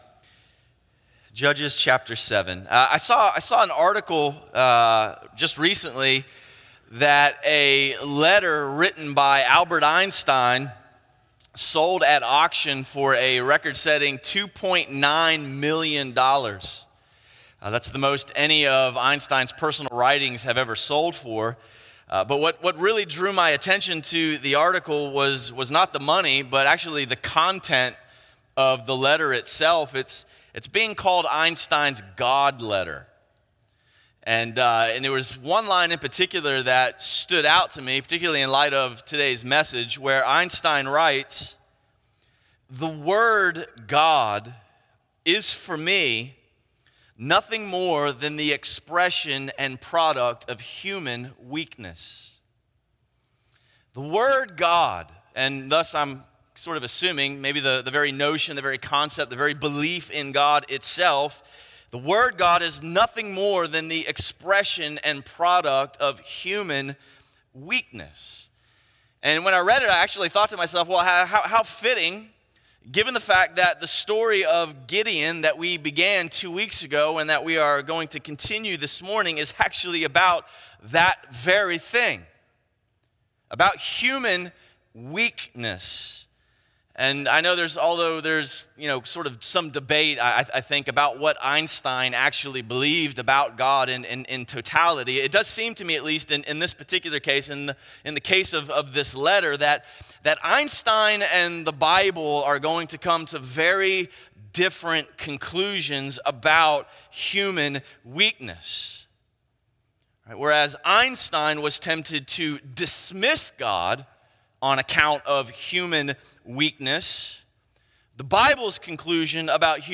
A message from the series "Ekklēsía."